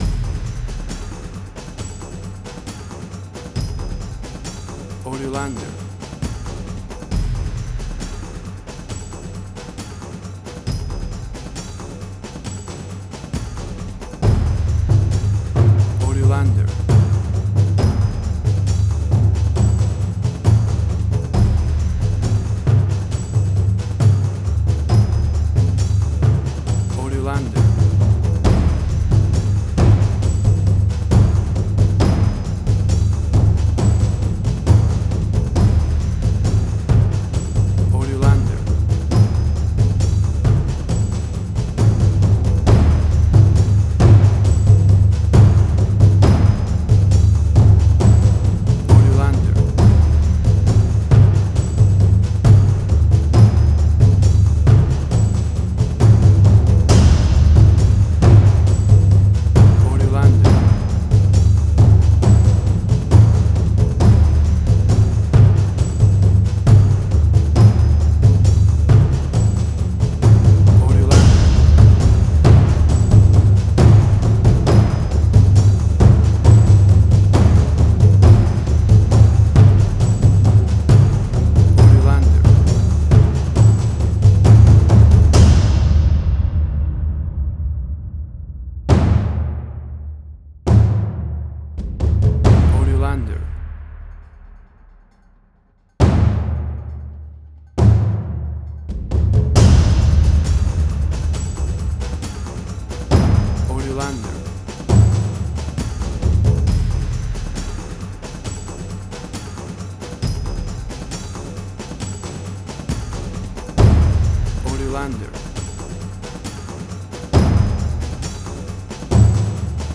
Exotic and world music!
Tempo (BPM): 136